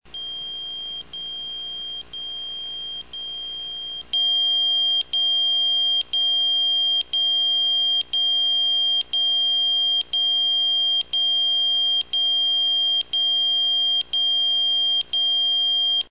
Les bips POCSAG, ce sont des BIPs dit "numérique
alerte
sonnerie